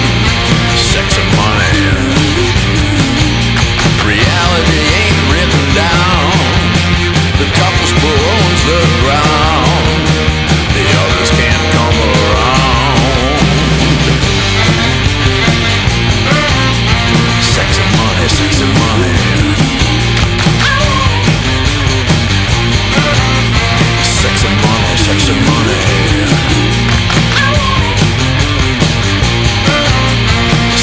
rock
guitariste
batteur
basse
album studio